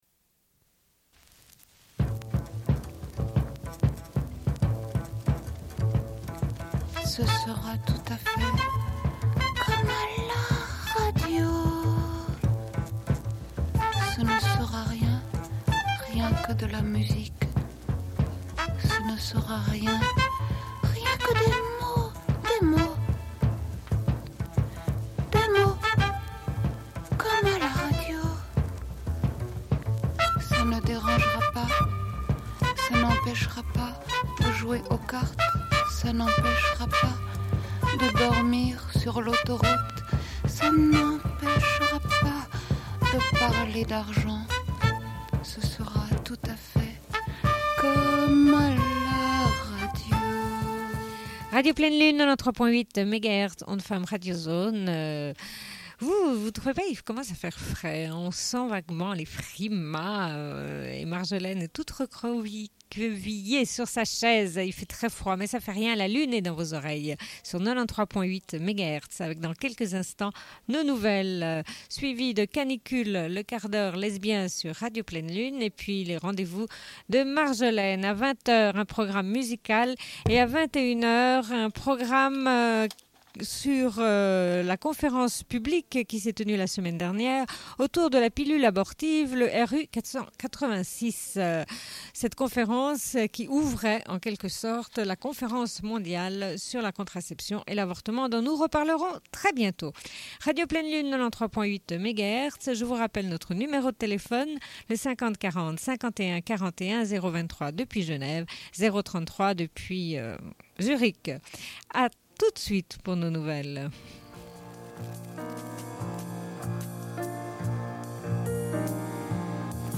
Une cassette audio, face B29:31